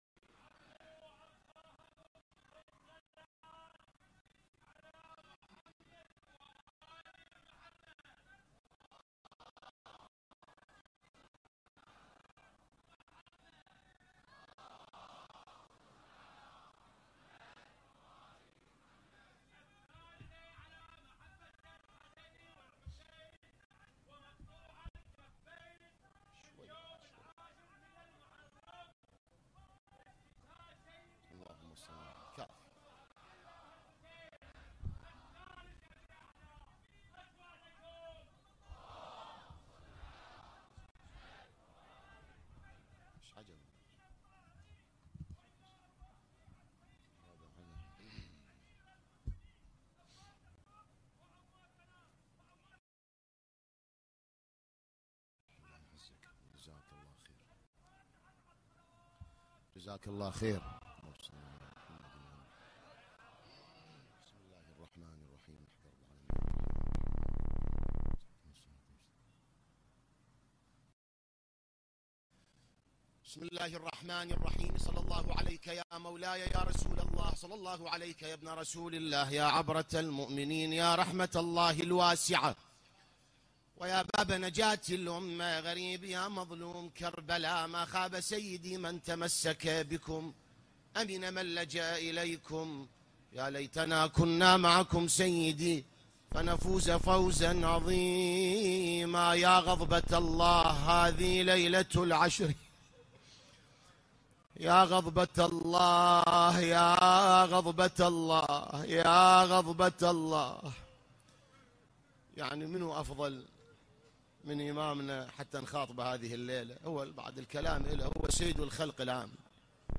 الان-مباشرة-من-النجف-الاشرف-ليلة-عاشوراء-١٤٤٦هـ-موكب-النجف-الاشرف.mp3